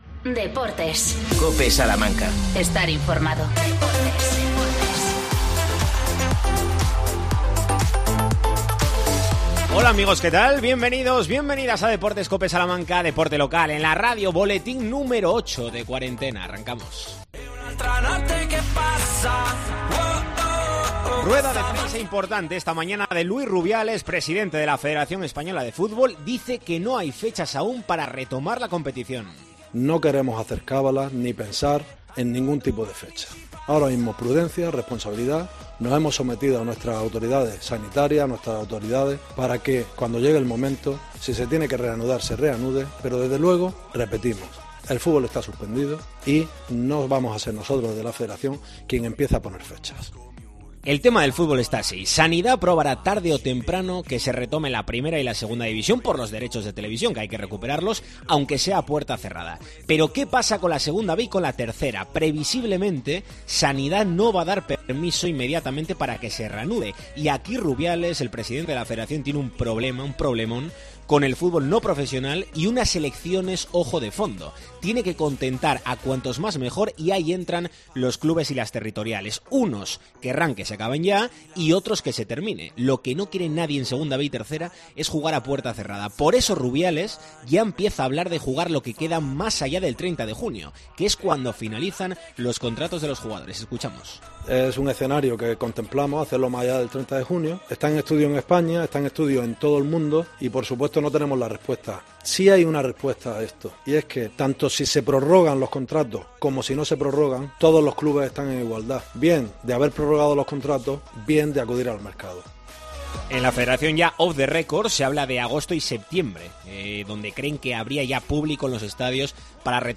AUDIO: Rueda de prensa de Luis Rubiales, presidente de la RFEF. ¿Cuándo volverá el fútbol en 2B?